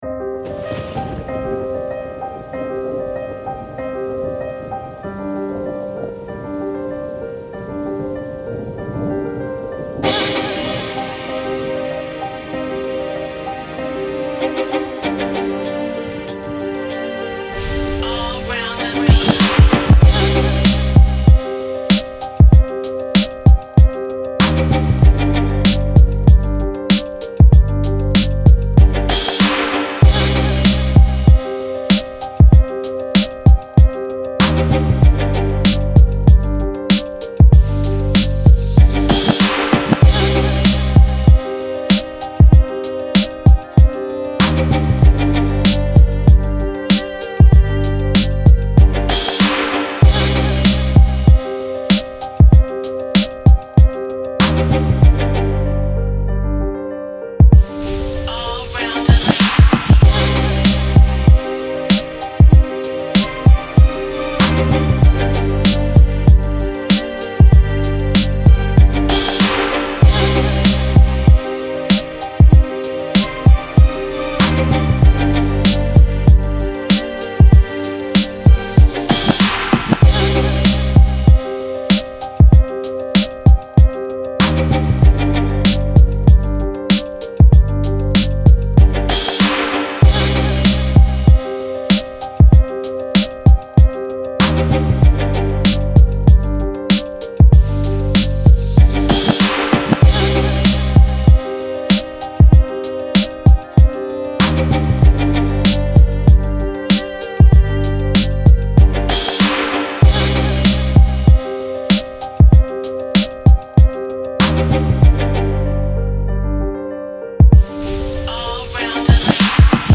ловите лучшие 10 минусов, пряных, качающих, сочных.
минус 3